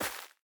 Minecraft Version Minecraft Version snapshot Latest Release | Latest Snapshot snapshot / assets / minecraft / sounds / block / hanging_roots / step3.ogg Compare With Compare With Latest Release | Latest Snapshot
step3.ogg